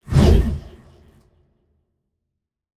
building_upgrade_2.wav